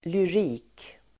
Ladda ner uttalet
Folkets service: lyrik lyrik substantiv, lyric poetry; lyrics Uttal: [lyr'i:k] Böjningar: lyriken Synonymer: poesi Definition: poesi Avledningar: lyriker (lyricist) Relaterade ord: prosa (antonym) (prose)